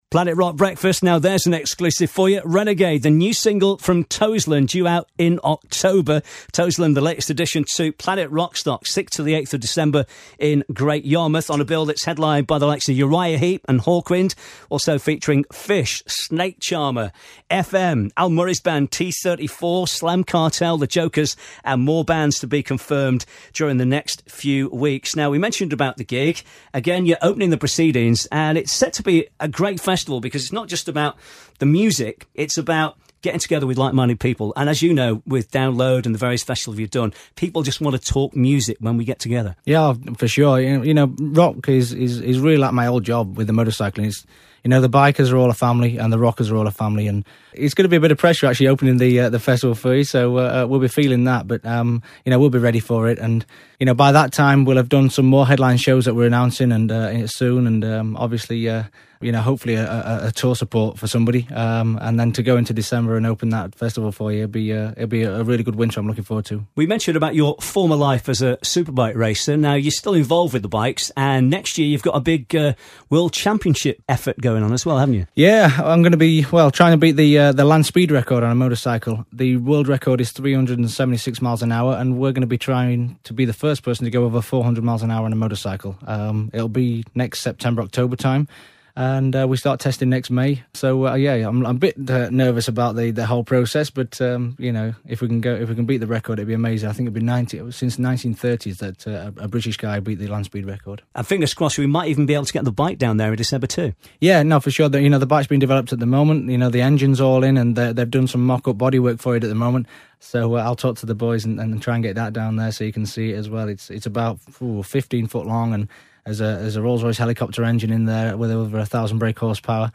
Planet Rockstock: Toseland Interview (2/2)